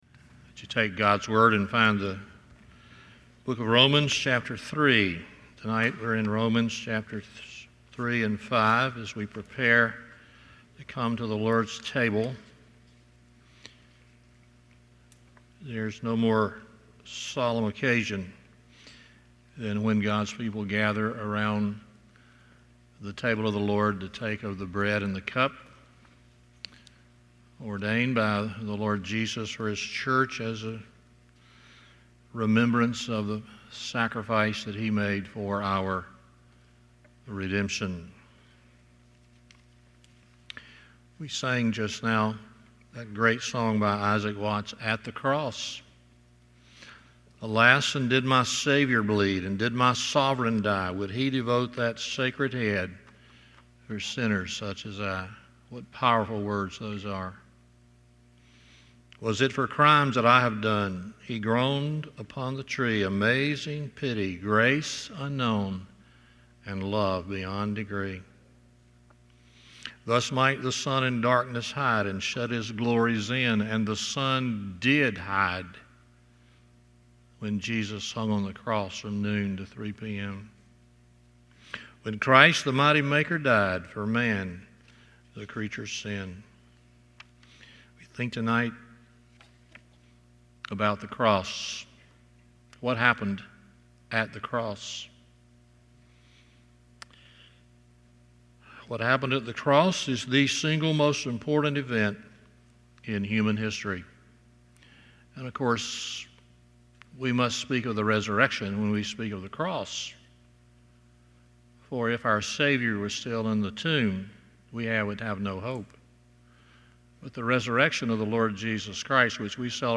Stand Alone Sermons
Service Type: Sunday Evening